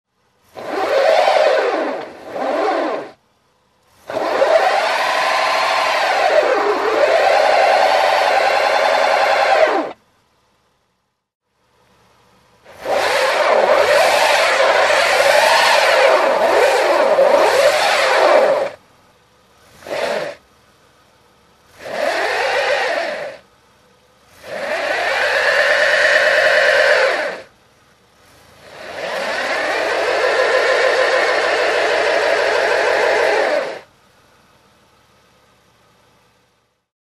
На этой странице собраны реалистичные звуки пробуксовки автомобиля в разных условиях: на льду, в грязи, на мокром асфальте.
Машина буксует на укатанном снегу и гололеде